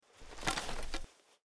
skyrim_bow_draw.mp3